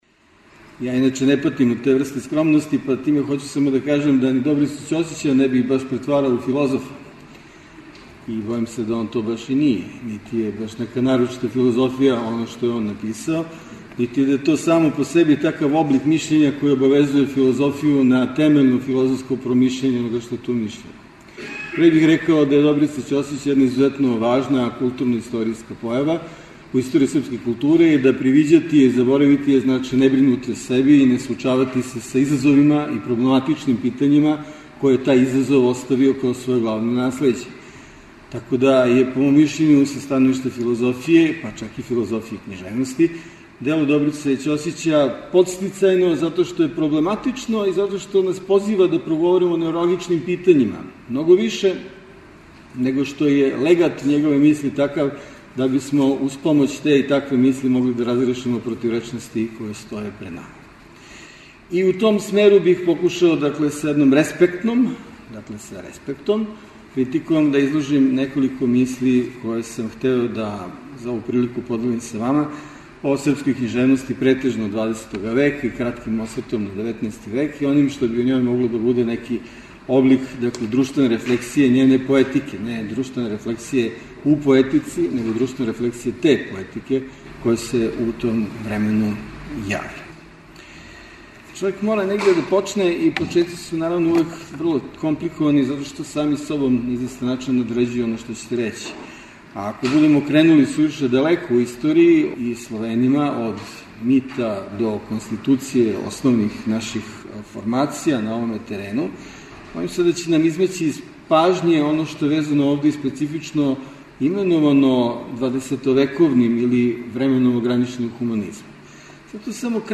У циклусу НАУЧНИ СКУПОВИ четвртком ћемо емитовати снимке са 27. Крушевачке филозофско-књижевне школе, чија тема је била 'ОД КОРЕНА ДО ДЕОБА – ДРУШТВЕНА СЛИКА СРБИЈЕ У 20. ВЕКУ'.
У оквиру Видовданских свечаности града Крушевца 17. и 18. јуна у Културном центру Крушевац одржана је 27. Крушевачка филозофско-књижевна школа.